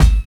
50 KICK 5.wav